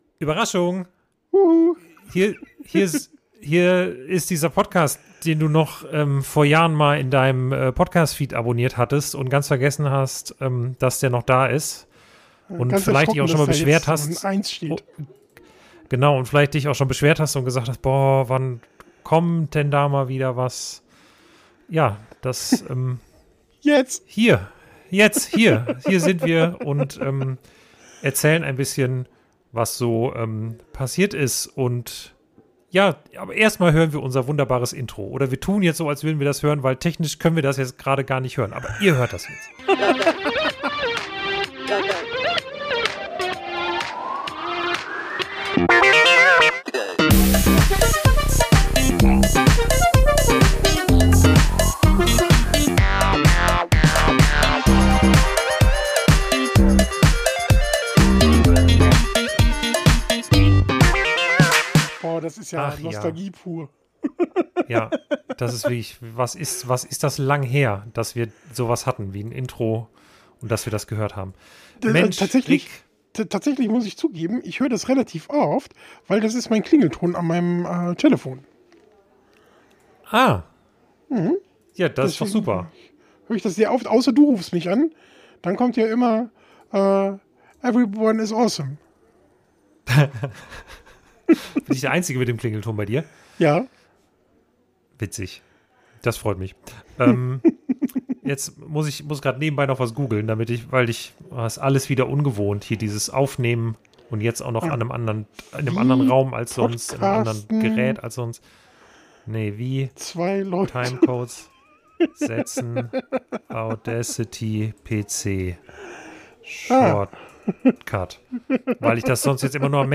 Wir erzählen, was aktuell so bei uns los ist, warum es so lange keinen Podcast gab und welche Alternativen wir euch aktuell so bieten können. Und wir sprechen über die (rosige) Zukunft des Podcasts und über all die Dinge, die uns im LEGO Jahr 2024 bisher beschäftigt haben. Die erste Folge nach der sehr langen Sendepause ist noch etwas unstrukturiert.